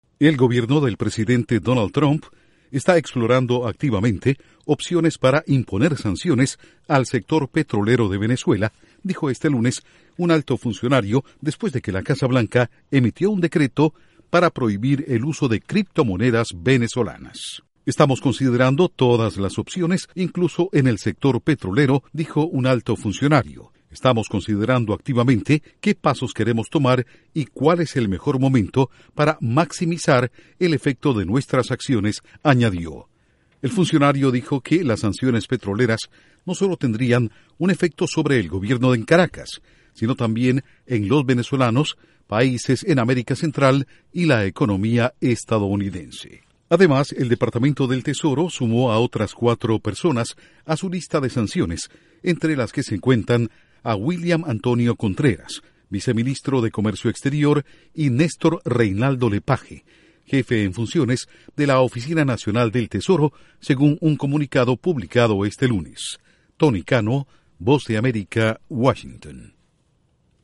Intro: Estados Unidos incluye más funcionarios y exfuncionarios del gobierno de Venezuela en su lista de sancionados. Informa desde la Voz de América en Washington